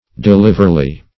Search Result for " deliverly" : The Collaborative International Dictionary of English v.0.48: Deliverly \De*liv"er*ly\, adv. Actively; quickly; nimbly.